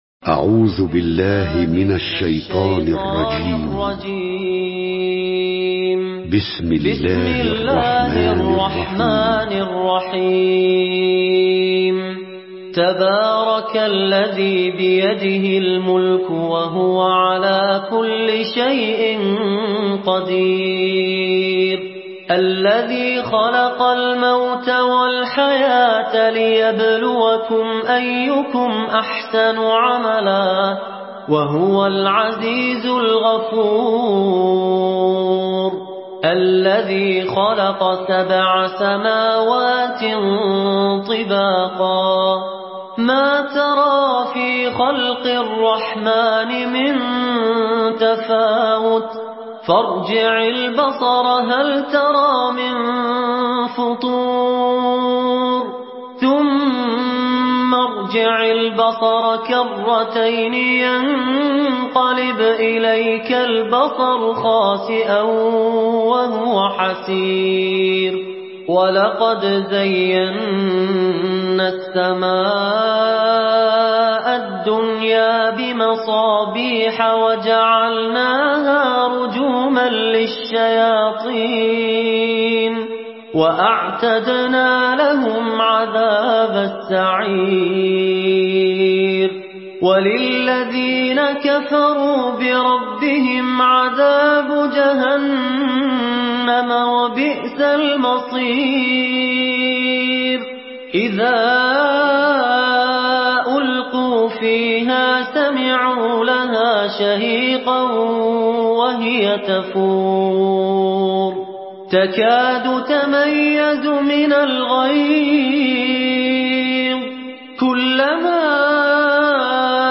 Surah আল-মুলক MP3 by Fahad Alkandari in Hafs An Asim narration.